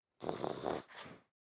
Minecraft Version Minecraft Version snapshot Latest Release | Latest Snapshot snapshot / assets / minecraft / sounds / mob / fox / sleep1.ogg Compare With Compare With Latest Release | Latest Snapshot
sleep1.ogg